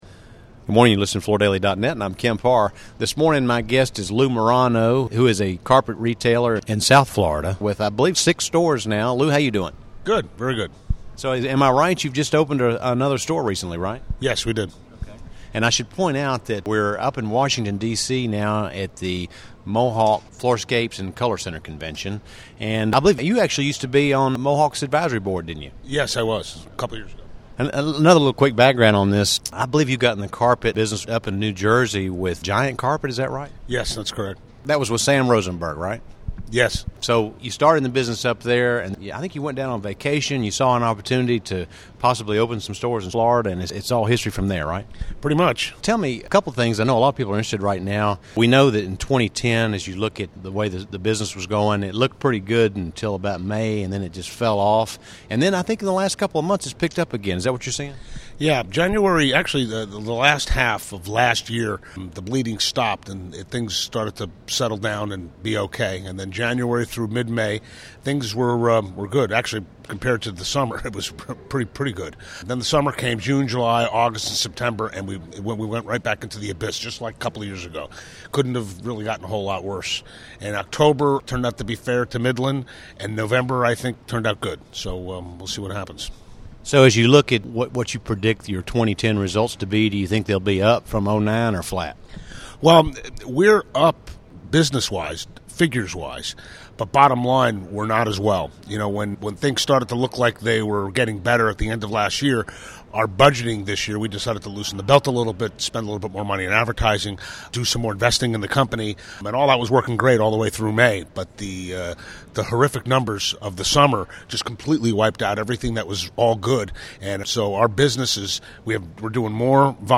Listen to the interview to hear details around his promotion efforts, store expansion, and what he gets out of his Mohawk Floorscapes relationship.